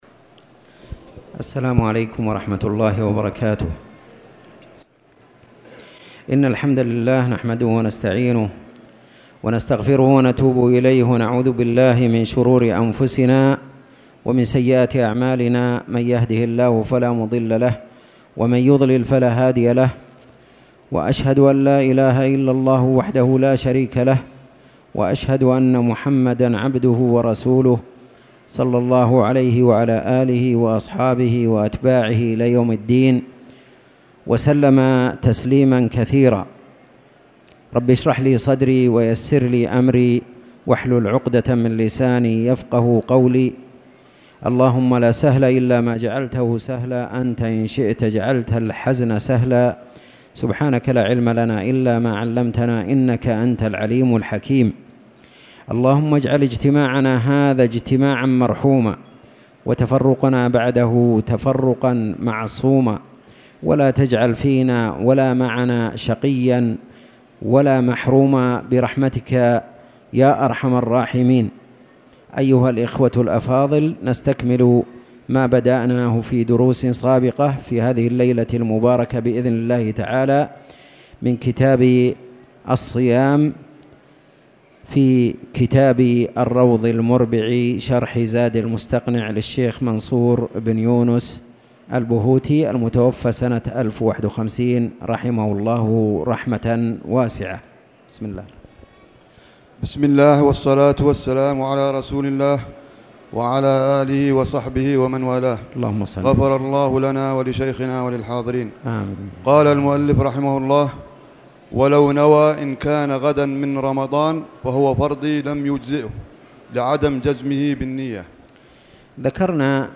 تاريخ النشر ٢١ ربيع الأول ١٤٣٨ هـ المكان: المسجد الحرام الشيخ